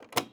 Switch (2).wav